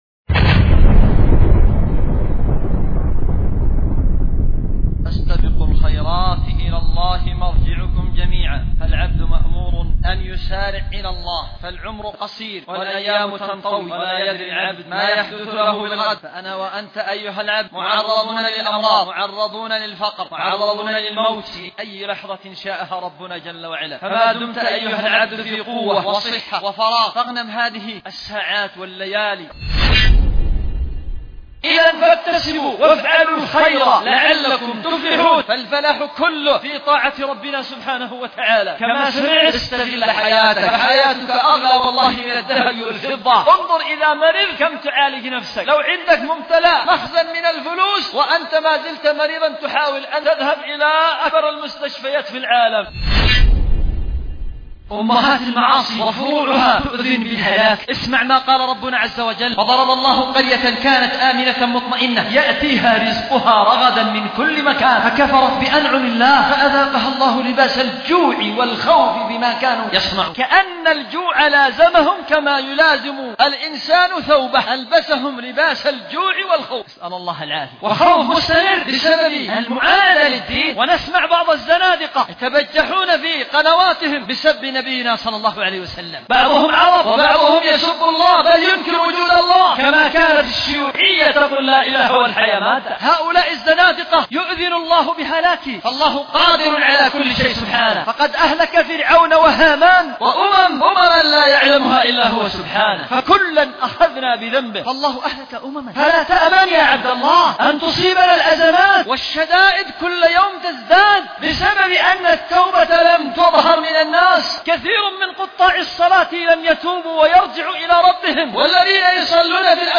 خطبة
في مسجد الخير بصنعاء